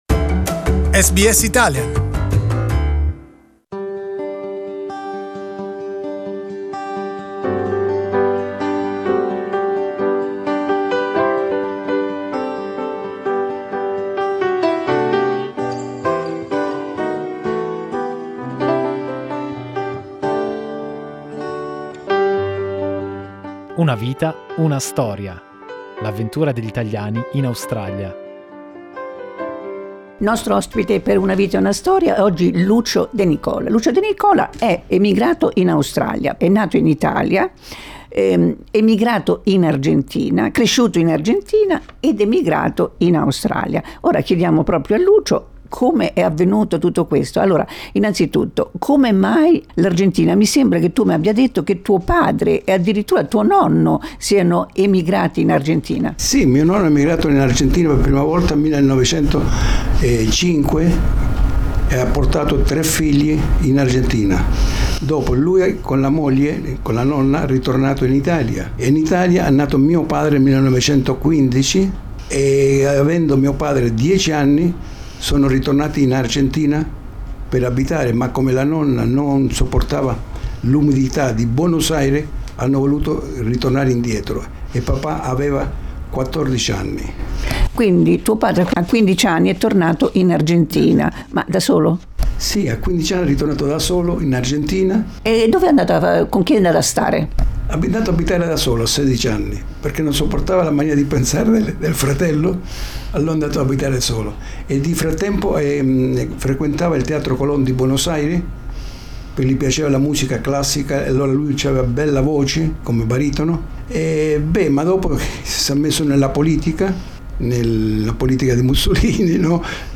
Part 1 of our interview